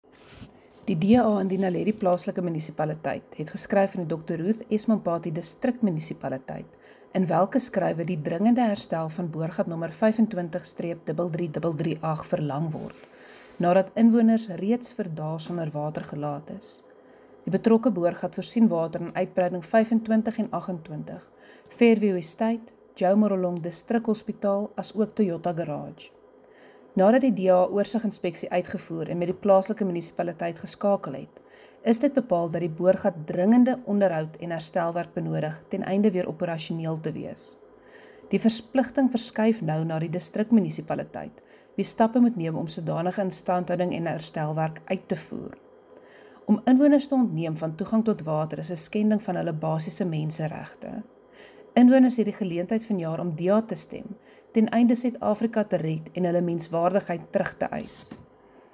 Issued by Cllr Hendriëtte van Huyssteen – DA Caucus Leader, Naledi Local Municipality
Note to Broadcasters: Please find linked soundbites in